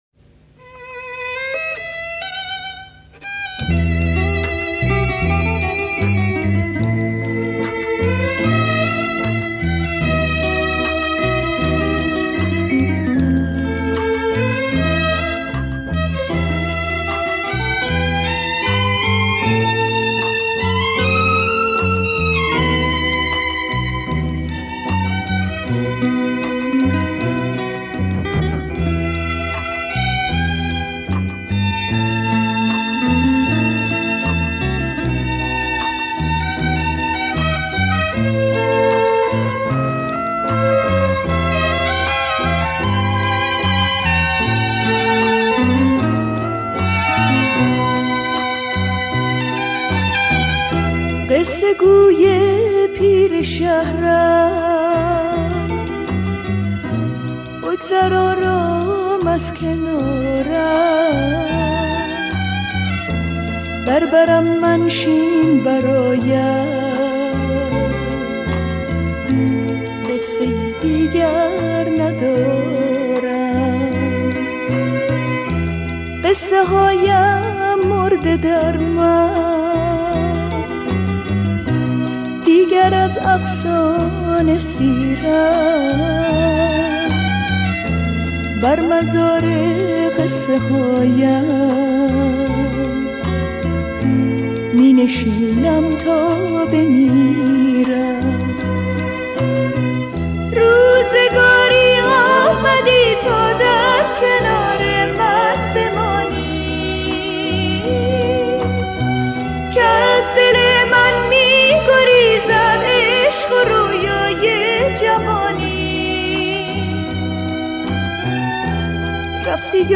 اهنگ غمگین
آهنگ قدیمی